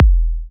edm-kick-11.wav